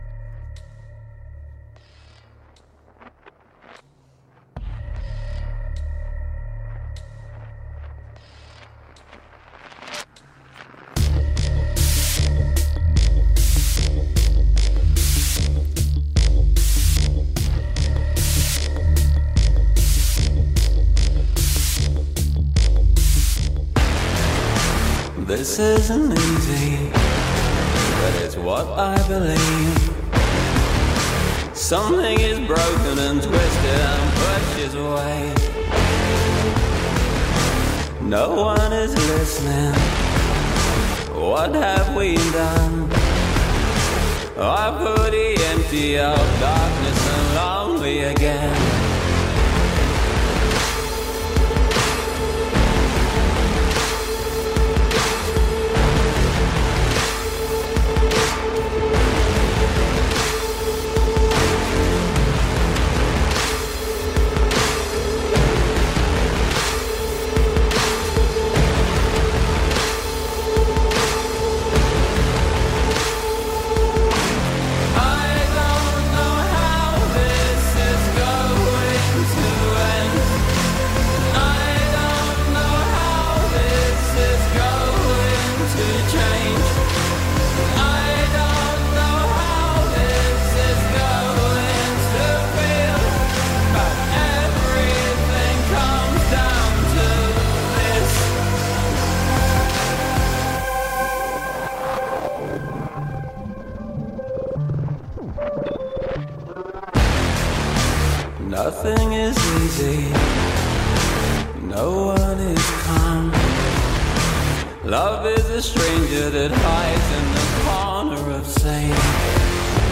Techno and Electronica Pioneers of the 70s and beyond.